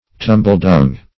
Search Result for " tumbledung" : The Collaborative International Dictionary of English v.0.48: Tumbledung \Tum"ble*dung`\, n. (Zool.)